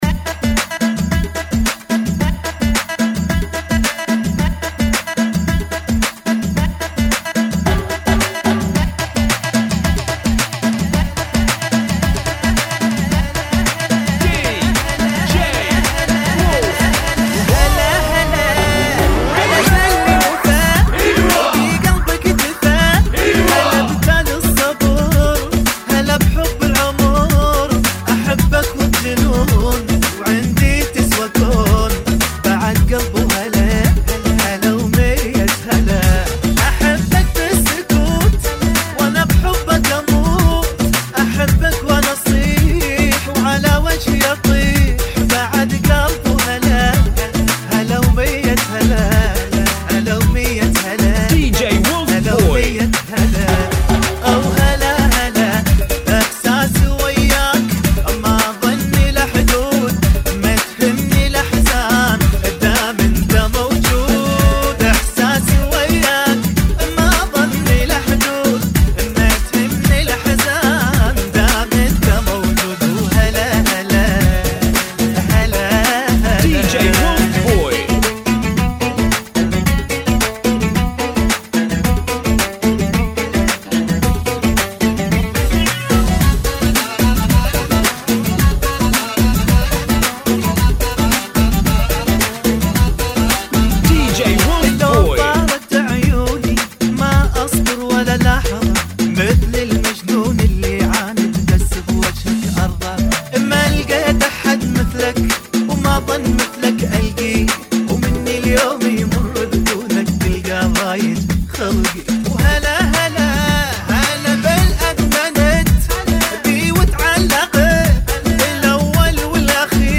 [ 110 Bpm ]